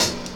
Medicated Hat 12.wav